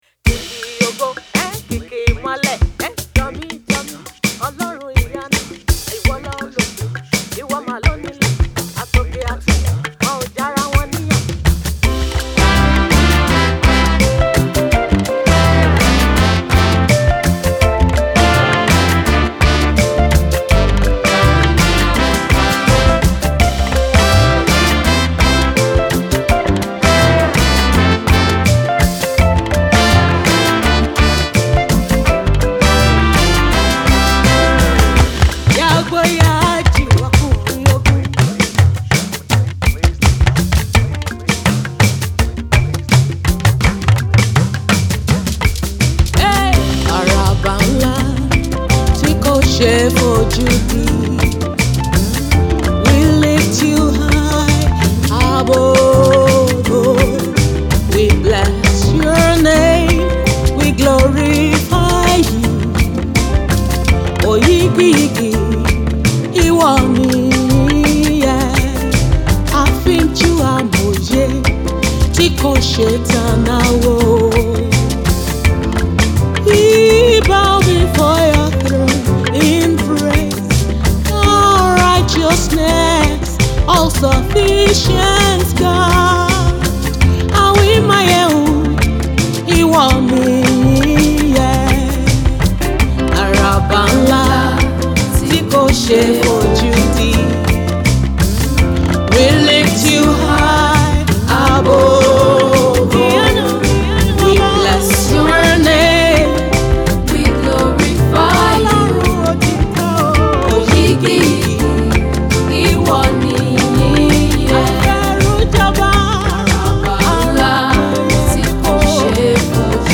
Praise and Worship singer